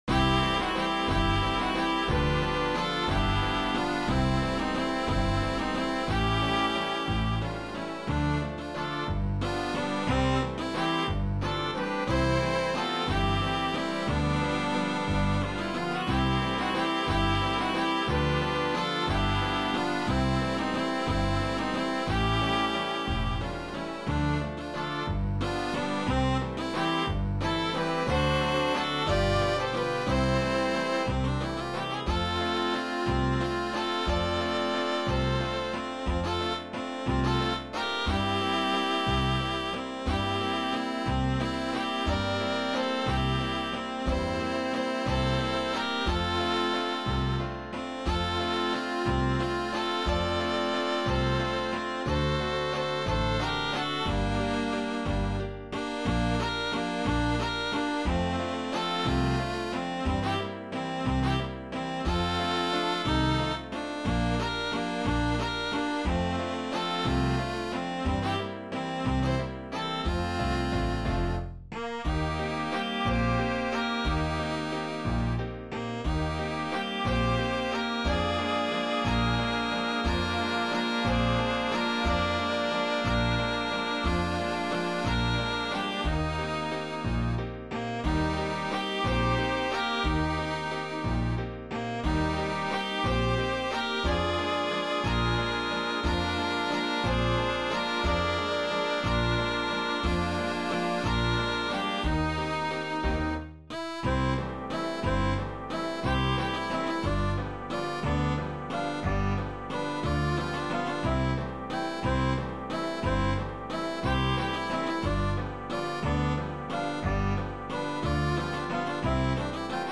There were the march and waltz medleys, originally conceived for performance at sports. The waltzes began with Copenhagen, slipping into Hi Lili, My Baby's Coming Home, Skaters Waltz, Cuckoo, and Stay Ladies Stay from Ali Baba and the 40 Black Sheep
Waltzes.mp3